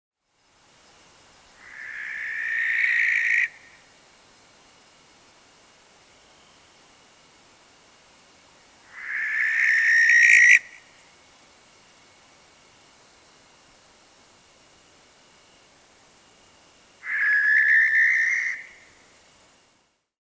На этой странице собраны звуки сипухи — уникальные резкие крики, которые чаще всего издает самец во время полета или для привлечения самки.
Голос обычной сипухи